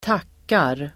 Uttal: [²t'ak:ar]